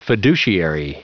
Prononciation du mot fiduciary en anglais (fichier audio)
Prononciation du mot : fiduciary